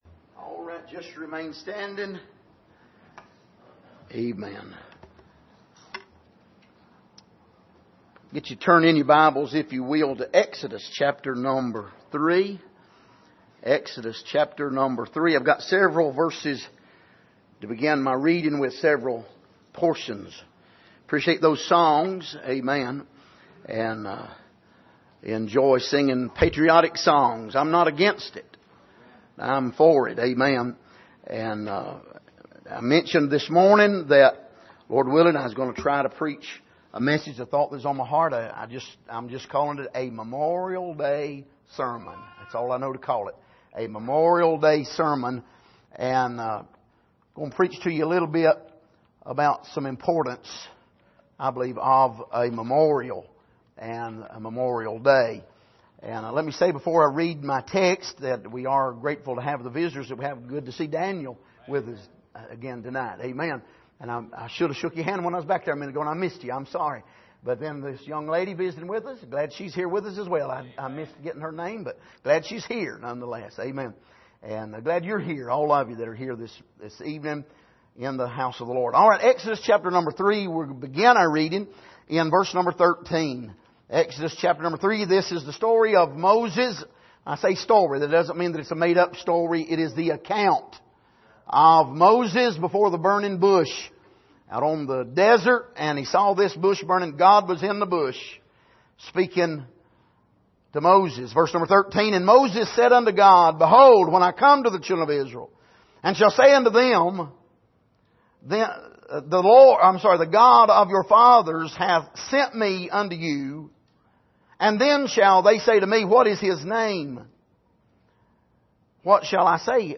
Passage: Exodus 3:13-15 Service: Sunday Evening